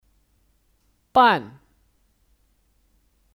办 (Bàn 办)